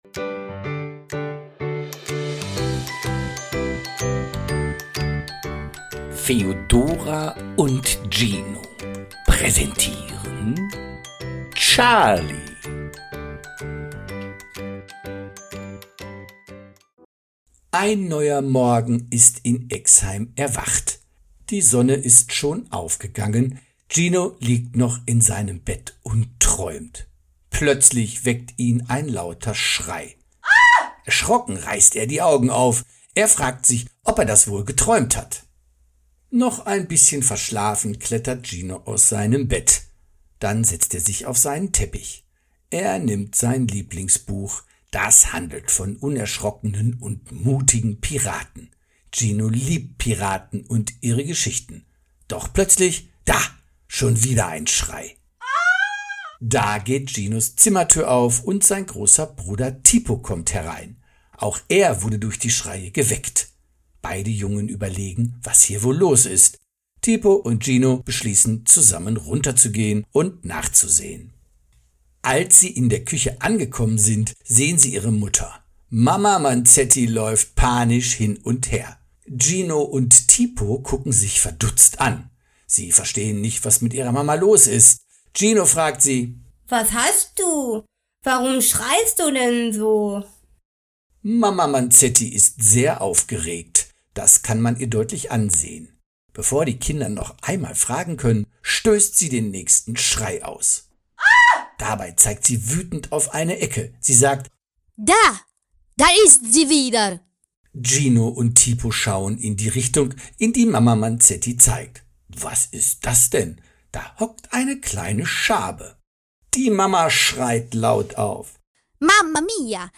Charlie – The audio play based on the popular picture book!
A lovingly narrated version full of emotion, ideal for falling asleep, relaxing, or simply listening.